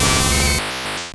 Cri de Raichu dans Pokémon Or et Argent.